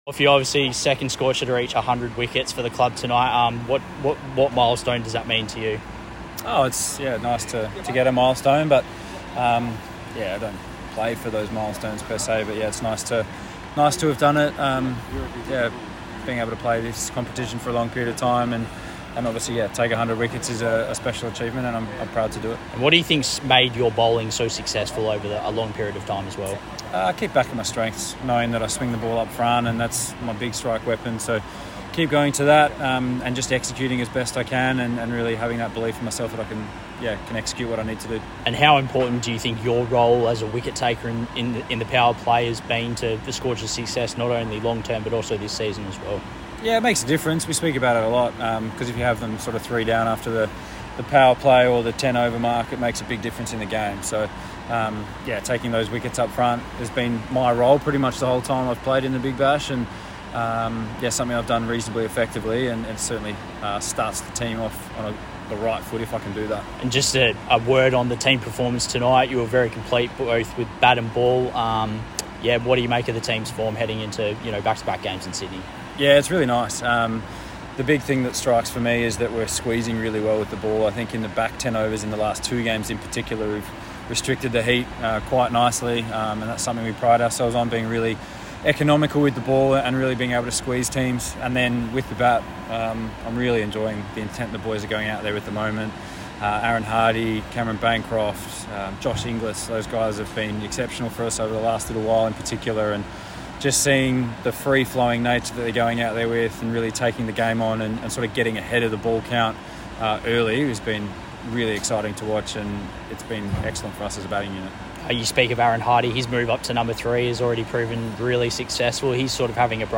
Perth Scorchers Jason Behrendorff speaks following win over Brisbane Heat